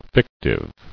[fic·tive]